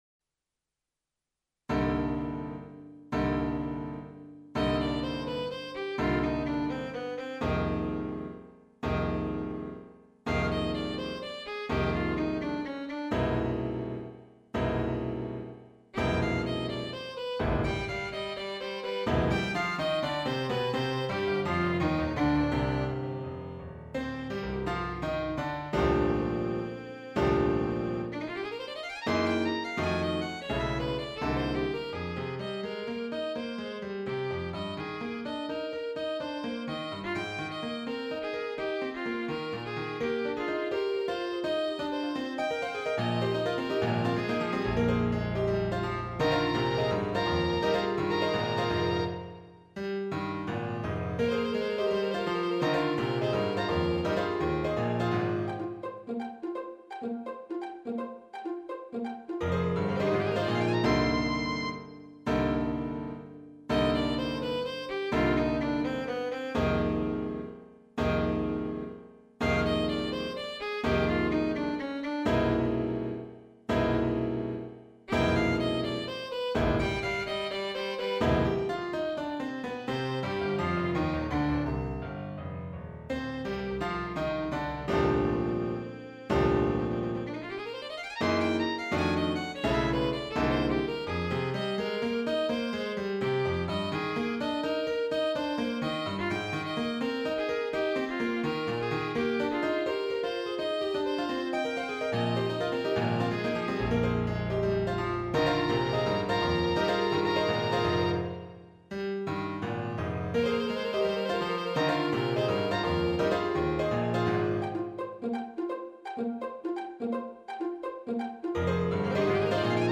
Type: Electronically Generated Location: Konstanz Date: 2009